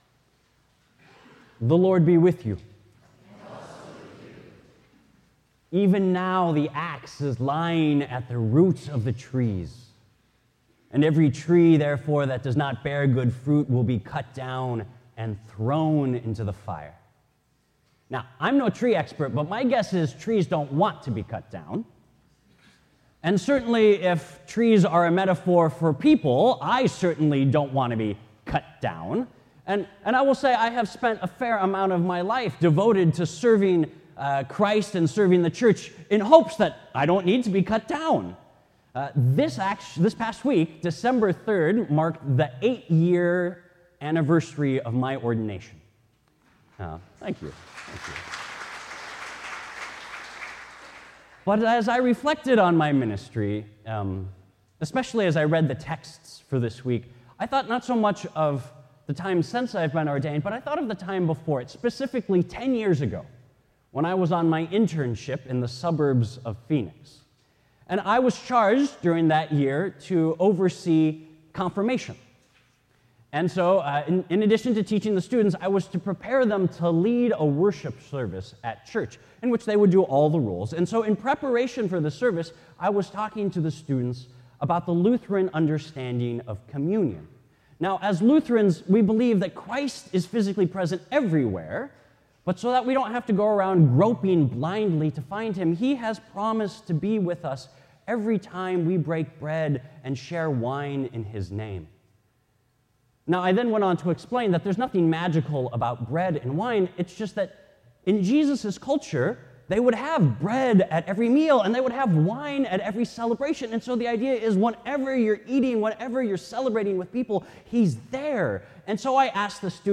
Sermons from Faith Lutheran Church | Faith Lutheran Church